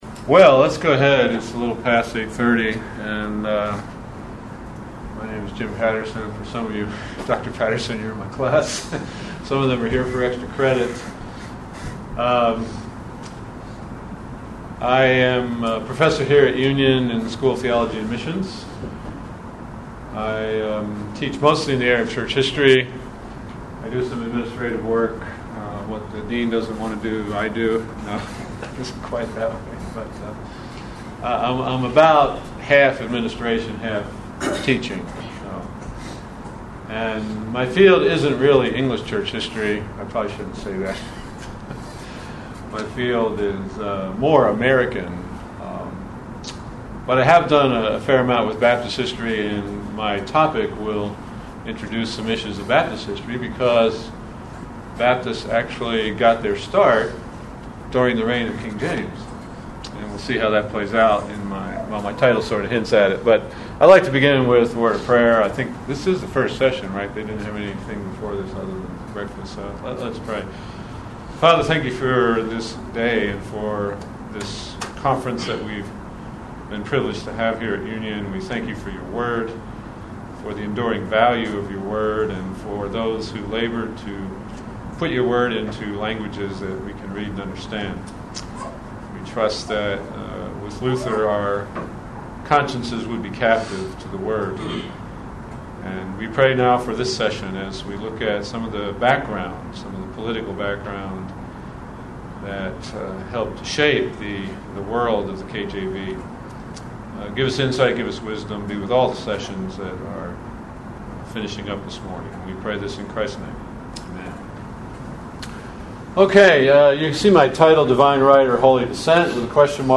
KJV400 Festival
Union University Address: Divine Right or Holy Dissent? Conflicting Visions of Church and State in Early Seventeenth-Century England